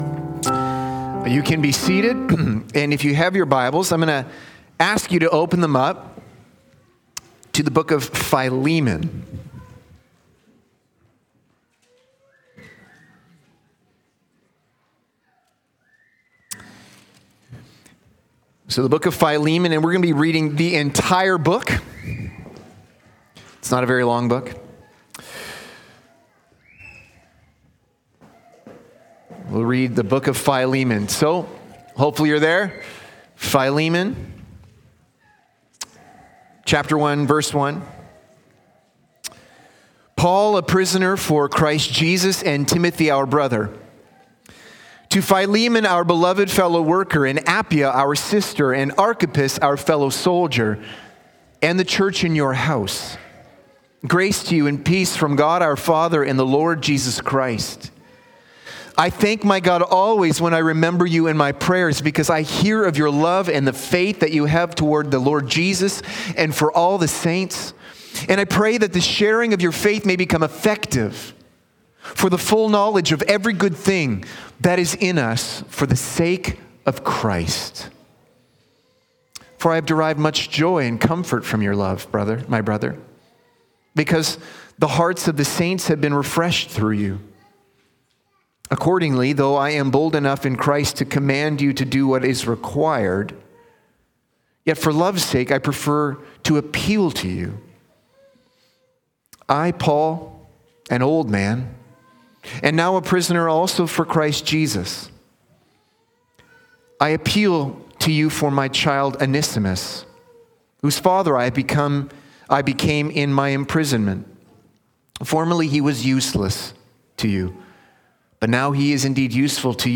Sermons | Park City Gospel Church